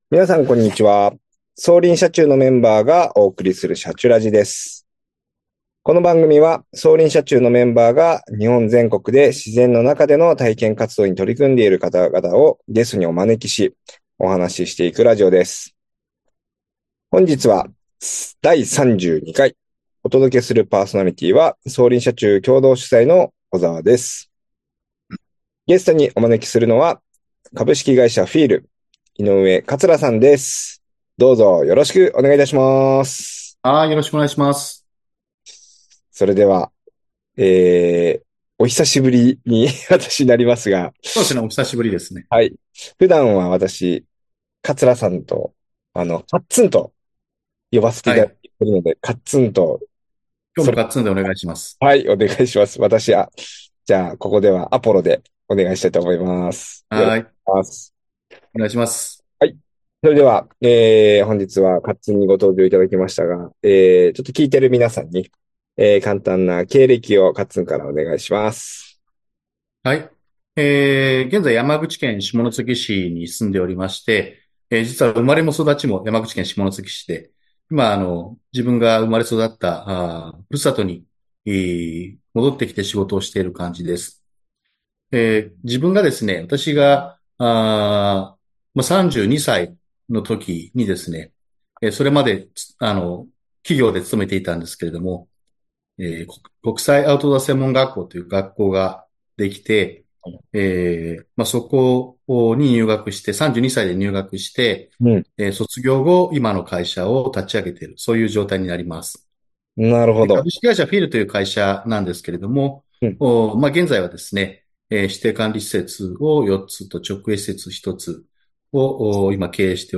「シャチュラジ」この番組は、走林社中のメンバーが日本全国で自然の中での体験活動に取り組んでいる方々をゲストにお招きし、お話ししていくラジオです。